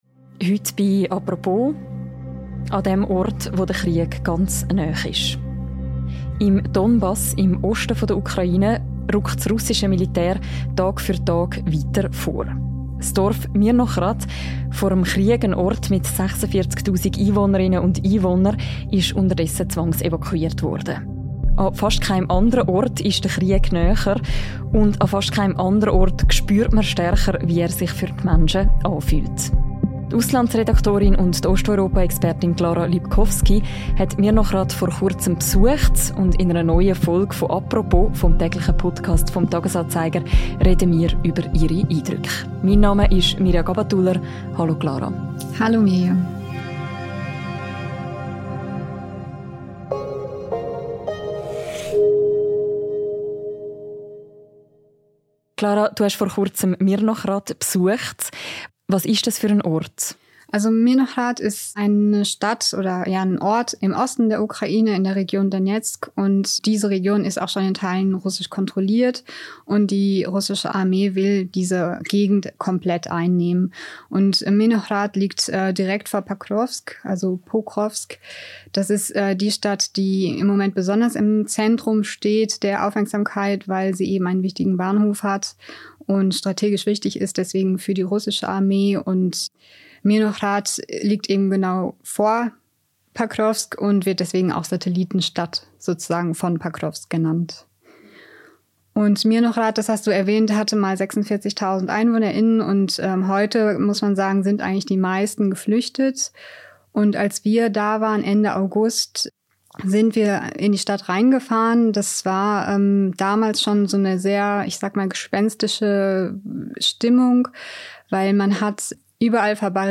Im Donbass bröckelt die Front, der ukrainischen Armee fehlt Personal und Kriegsmaterial. Eine Reportage aus einem Ort, wo die russische Armee nur noch wenige Kilometer weit entfernt ist.